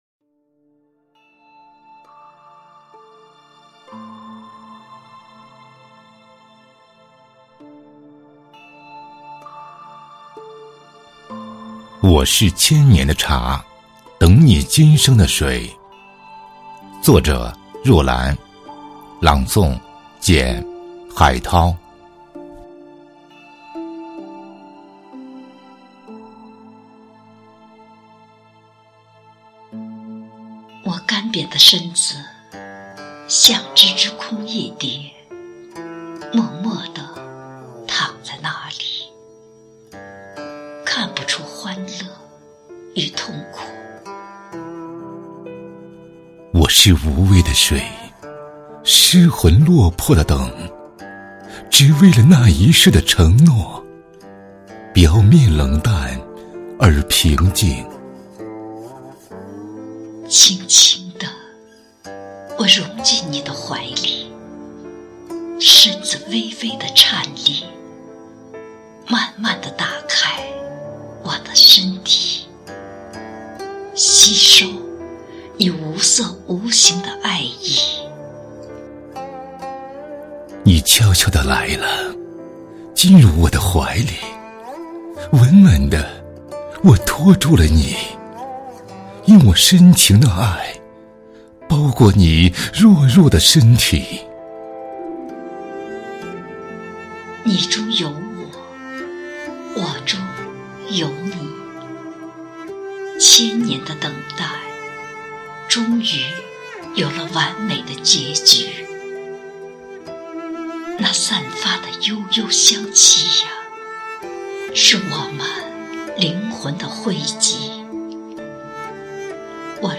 标题: 合诵：我是千年的茶，等你今生的水 [打印本页]